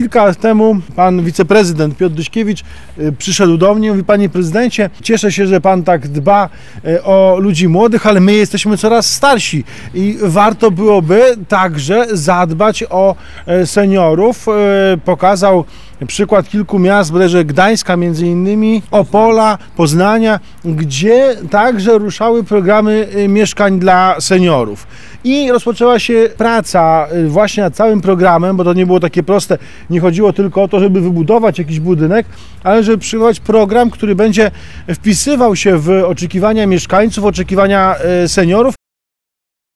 Jak przekazał prezydent Miasta Płocka Andrzej Nowakowski, pomysł na realizację tego projektu zapoczątkował kilka lat temu wiceprezydent Piotr Dyśkiewicz.